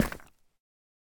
Minecraft Version Minecraft Version 1.21.5 Latest Release | Latest Snapshot 1.21.5 / assets / minecraft / sounds / block / deepslate / step1.ogg Compare With Compare With Latest Release | Latest Snapshot
step1.ogg